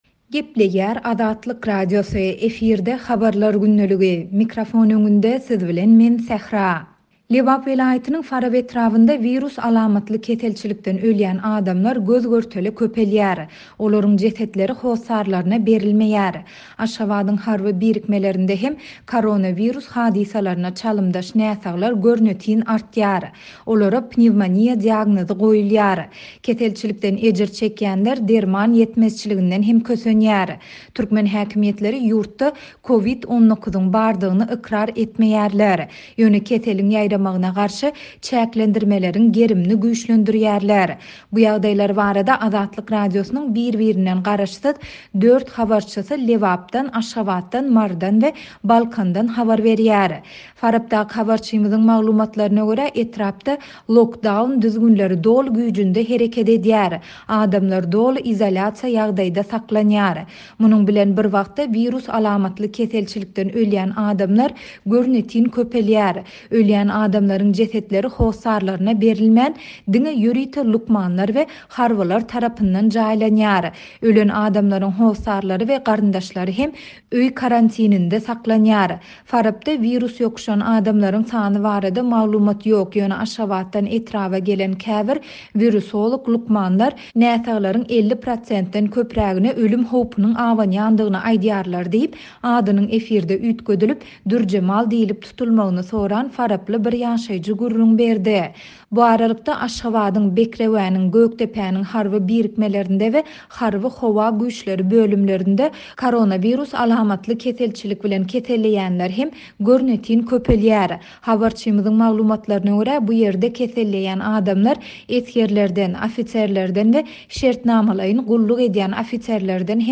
Türkmen häkimiýetleri ýurtda COVID-19-yň bardygyny ykrar etmeýärler, ýöne keseliň ýaýramagyna garşy çäklendirmeleriň gerimini güýçlendirýärler. Bu ýagdaýlar barada Azatlyk Radiosynyň biri-birinden garaşsyz dört habarçysy Lebapdan, Aşgabatdan, Marydan we Balkandan habar berýärler.